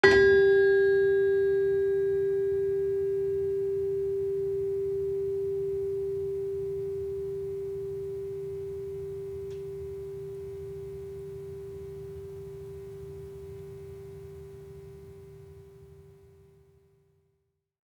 Gender-4-G3-f.wav